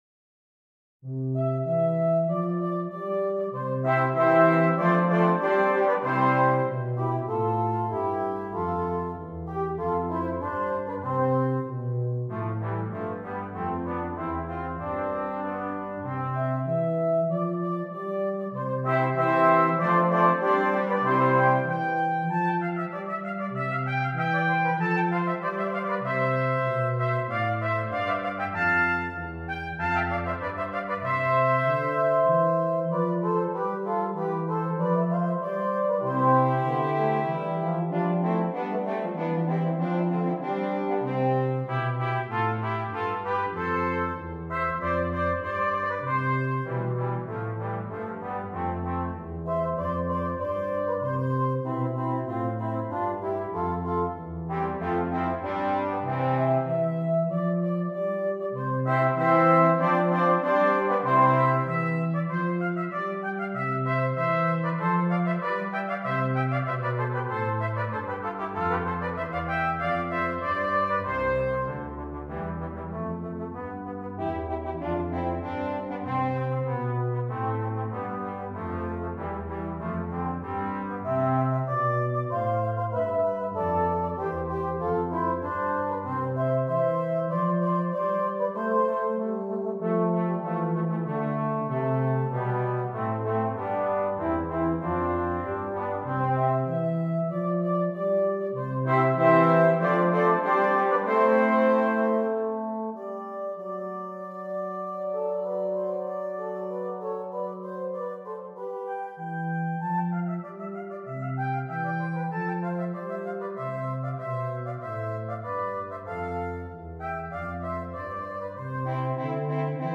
• Brass Choir (4.2.2.1.1)